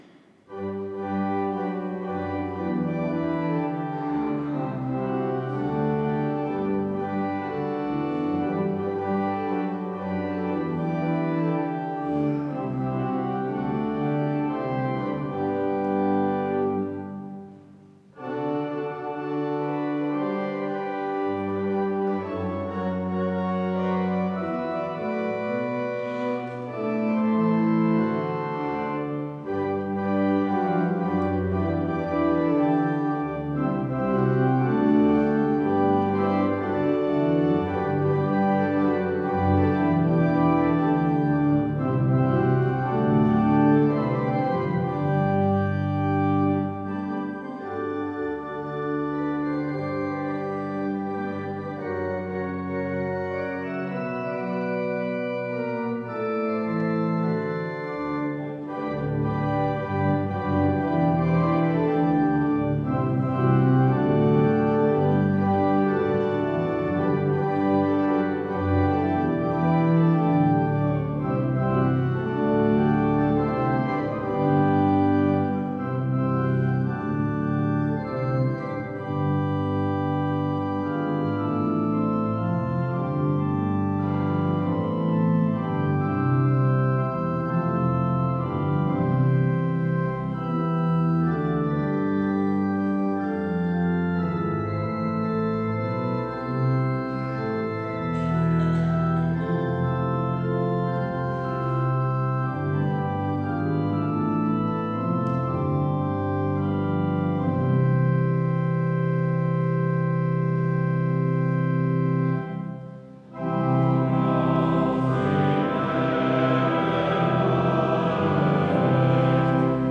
Audiomitschnitt unseres Gottesdienstes vom 2. Sonntag nach Ostern 2025.